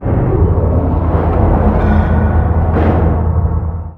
volcanoTransferBegin.wav